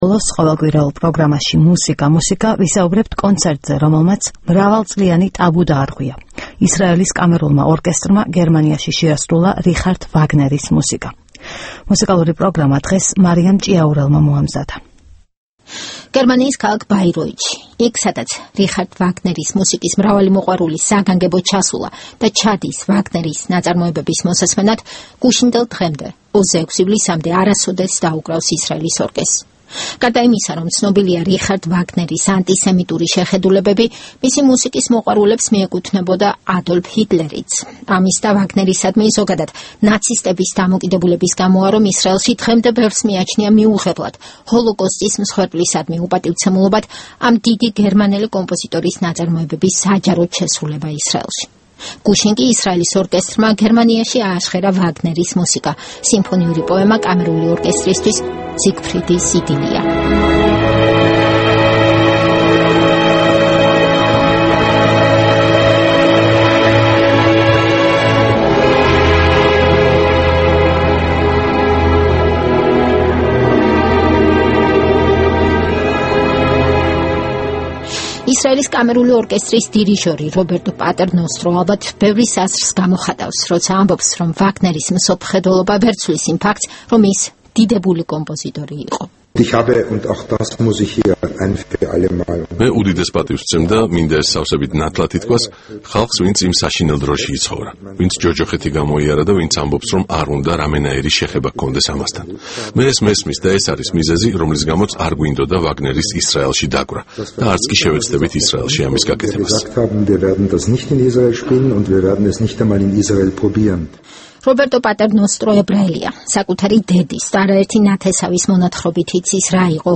ვაგნერი ისრაელის ორკესტრის შესრულებით